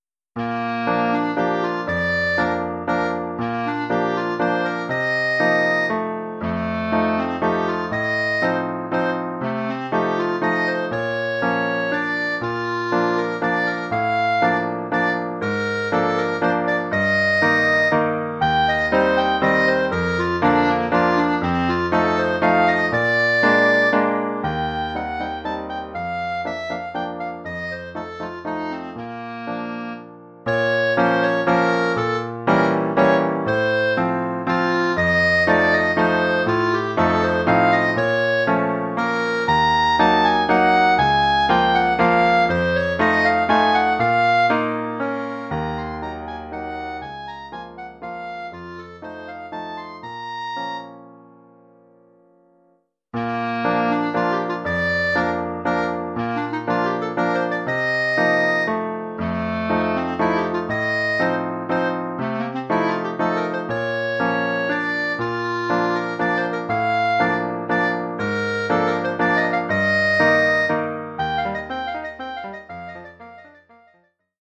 Oeuvre pour clarinette sib et piano.